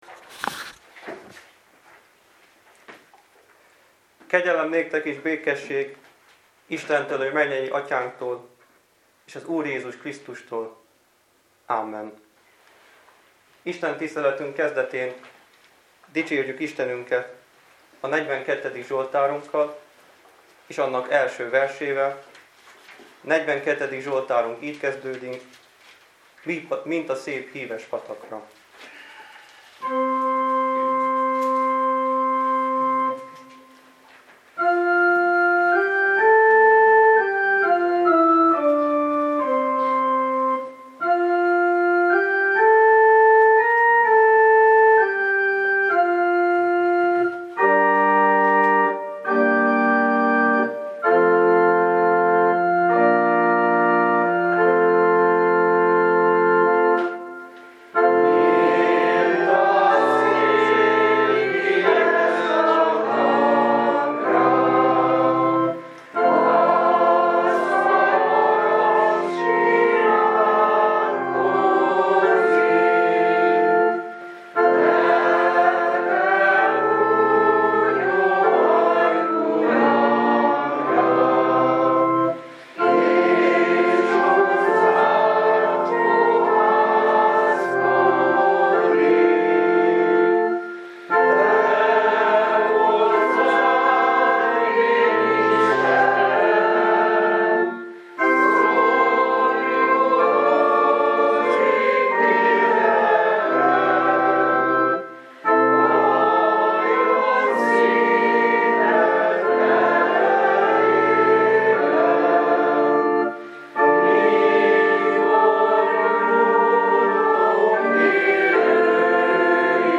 Vasárnapi istentisztelet